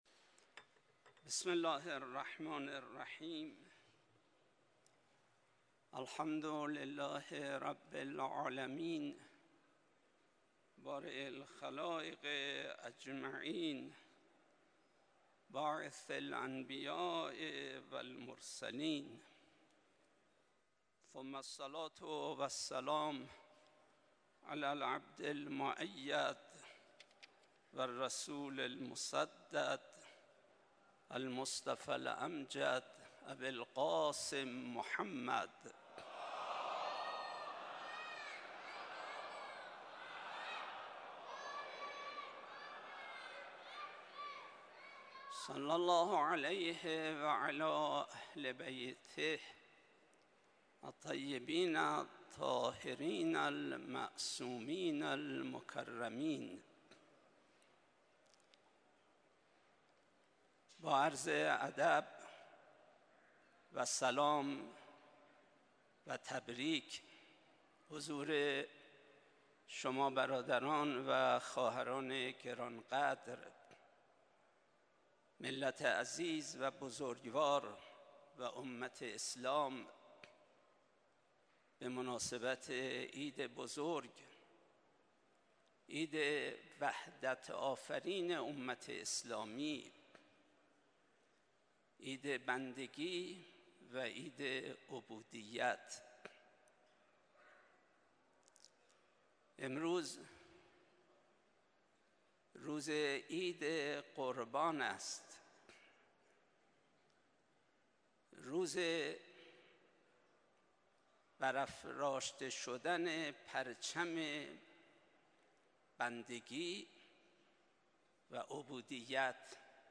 صوت | خطبه های حجت الاسلام و المسلمین ابوترابی‌فرد در نماز عید قربان تهران
حوزه/ نماز عید سعید قربان به امامت حجت‌الاسلام والمسلمین ابوترابی فرد امروز جمعه ۱۶ خرداد ۱۴۰۴ در دانشگاه تهران اقامه شد.